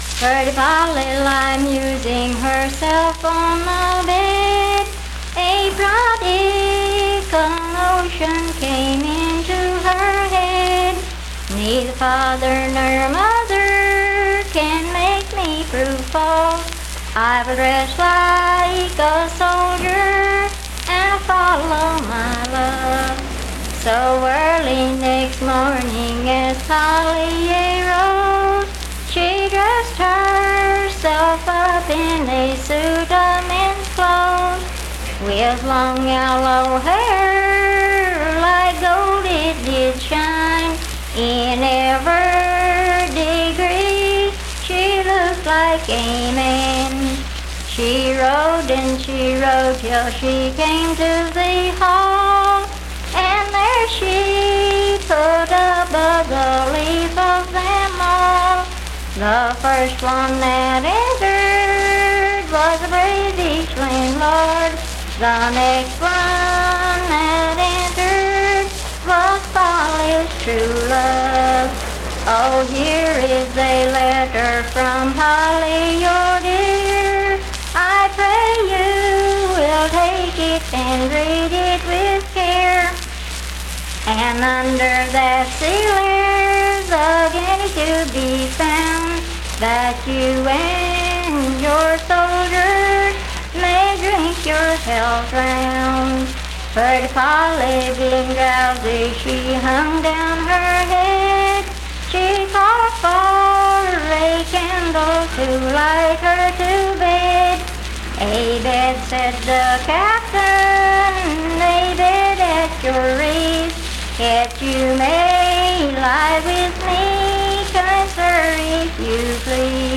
Unaccompanied vocal music
Voice (sung)
Spencer (W. Va.), Roane County (W. Va.)